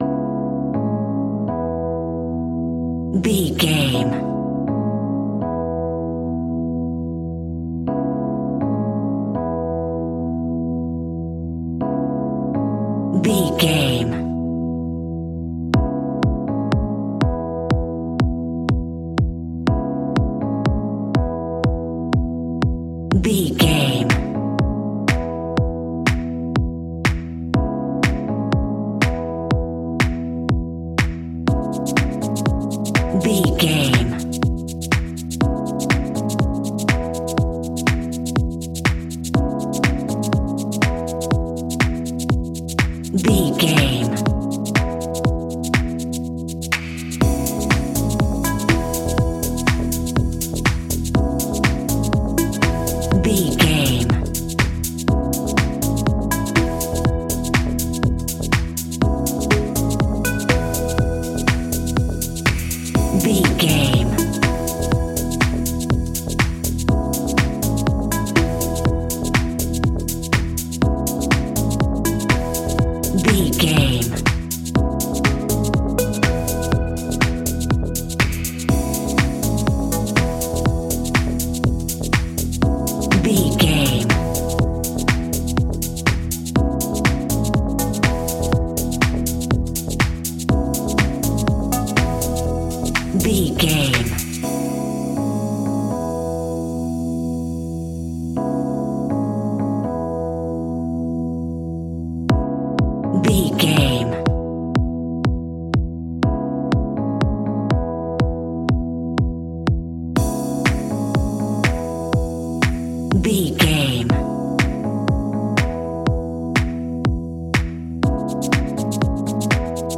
Ionian/Major
groovy
uplifting
driving
energetic
synthesiser
electric piano
drum machine
house
electro house
synth leads
synth bass